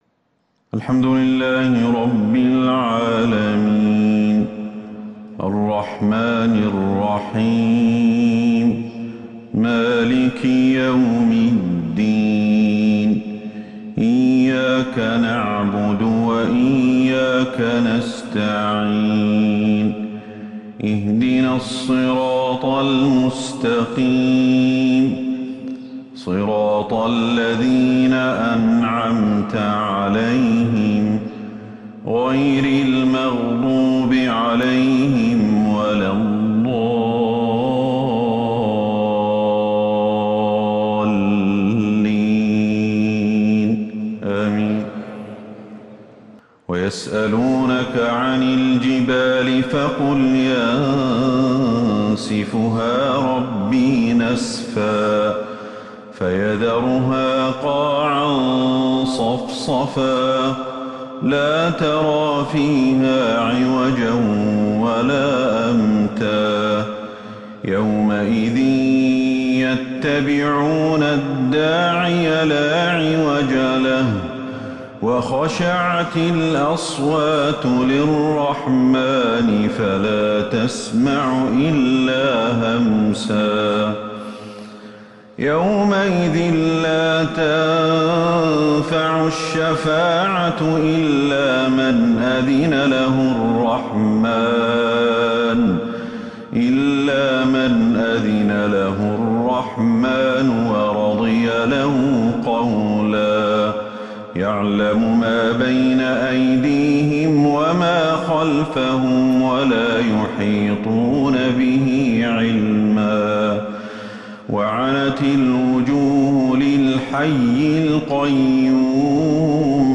فجر الثلاثاء 13 ربيع الأول 1443هـ آواخر سورة {طه} > 1443 هـ > الفروض - تلاوات الشيخ أحمد الحذيفي